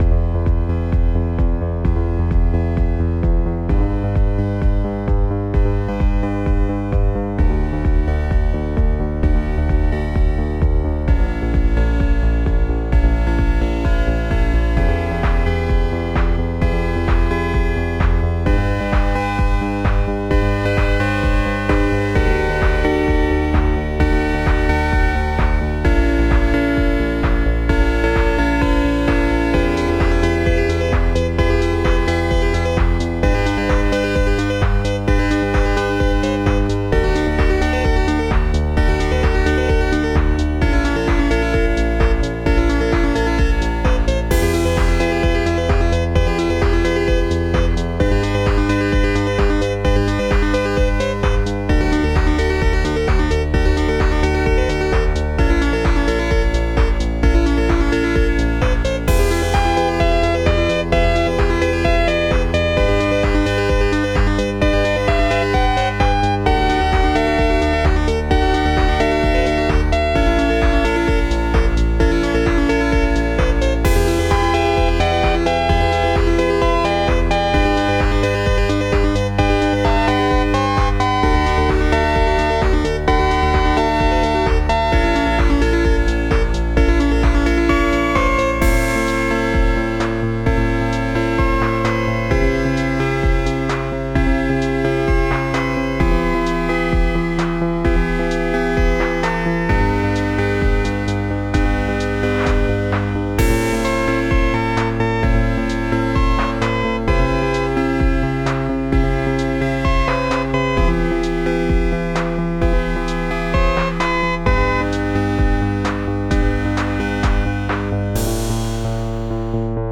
Mostly this is me messing with a new plug in
Percussion, Digital